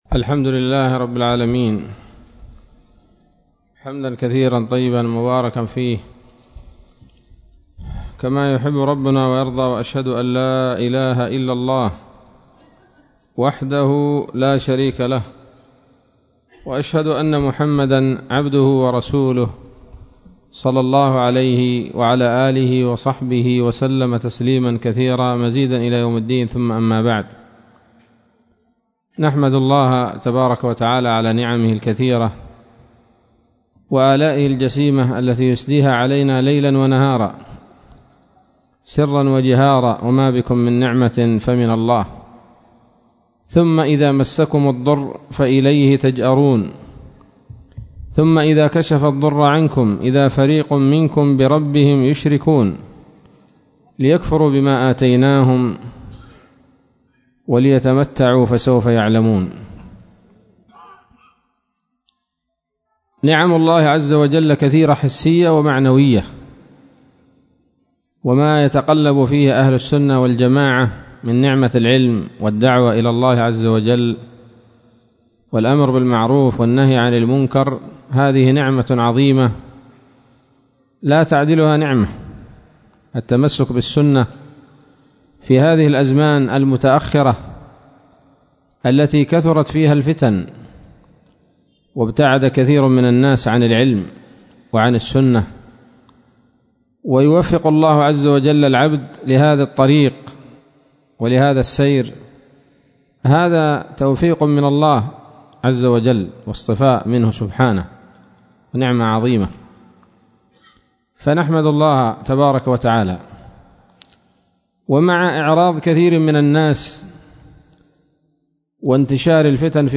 محاضرة بعنوان: (( شرح الرحلة إلى بلاد يافع)) ليلة الإثنين 20 من شهر ذي الحجة لعام 1441 هـ، بدار الحديث السلفية بصلاح الدين